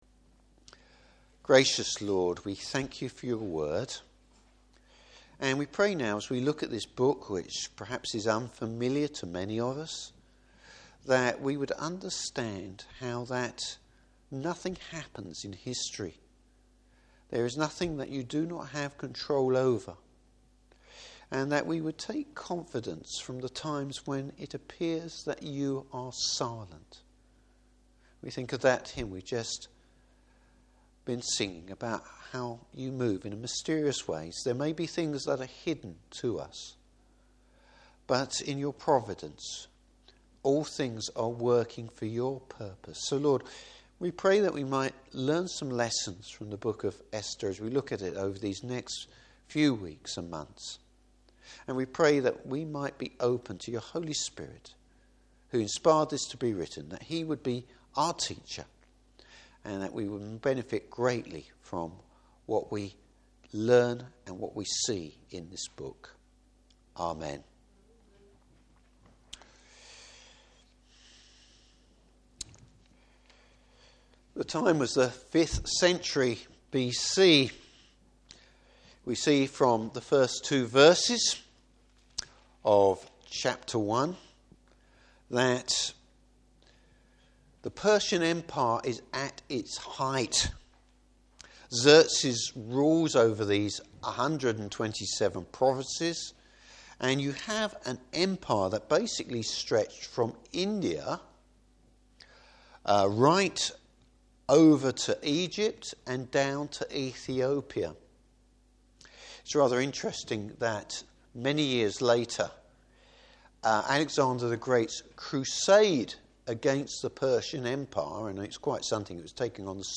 Service Type: Evening Service How God’s providence can be seen to be at work.